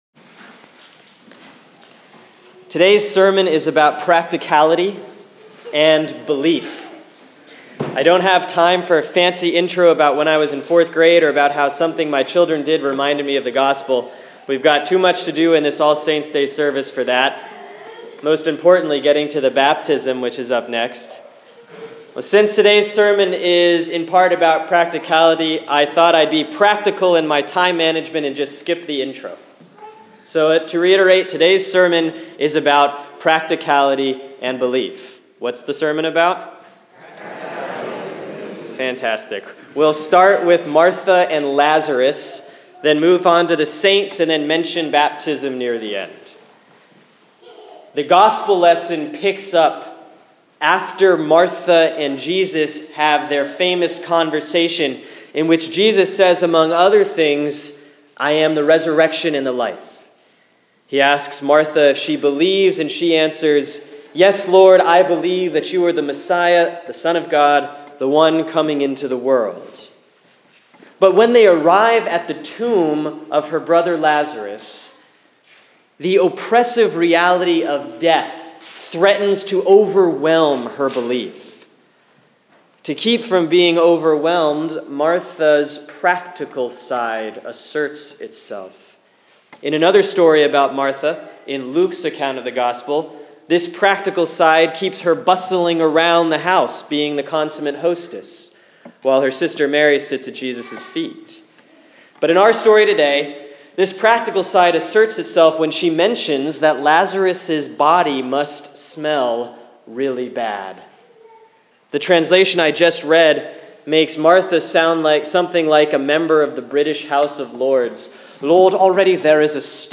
Sermon for Sunday, November 1, 2015 || All Saints’ Day Year B || John 11:32-44